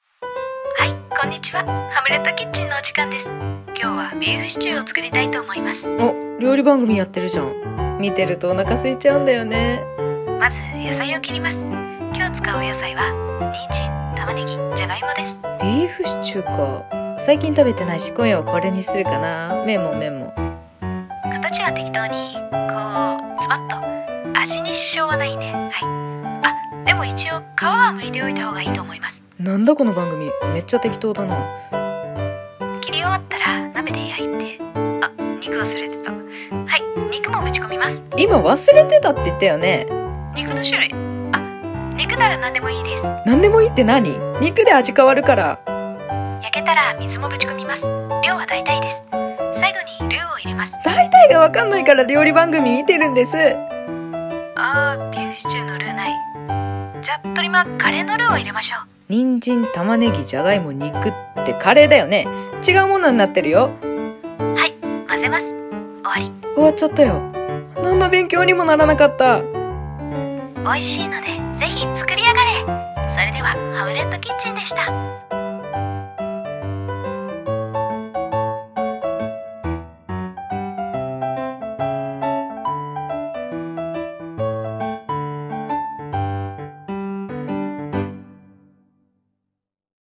【二人声劇】適当クッキング / 声劇